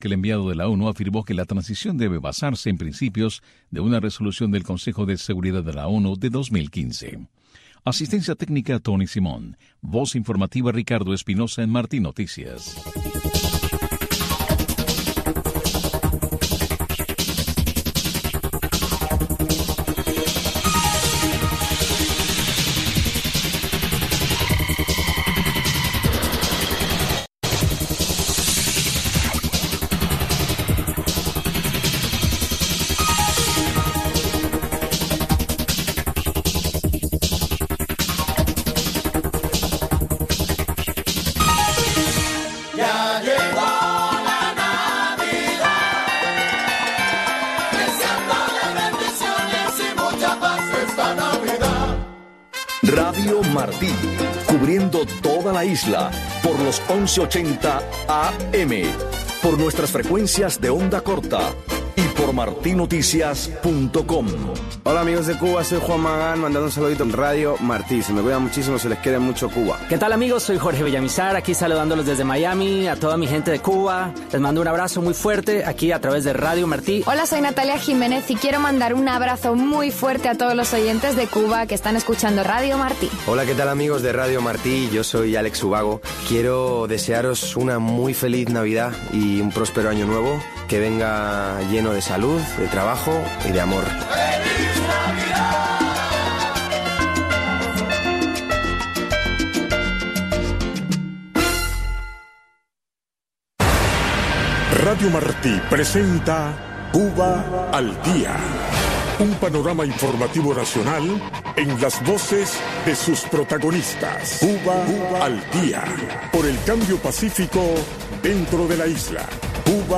espacio informativo en vivo, que marca el paso al acontecer cubano.